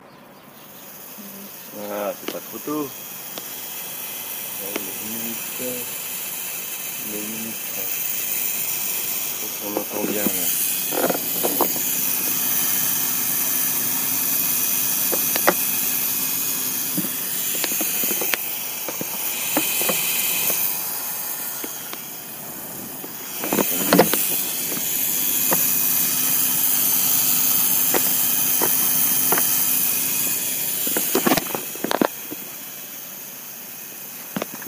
Le grésillement se répercute jusque dans le compteur électrique et toutes les prises de courant de la maison.
Alors voici le son du groupe, puis le concerto lisseur et compteur électrique.
Entre 25.82 et 25.83, il y a 10 ms. Le son a une structure assez répétitive justement toutes les 10 ms.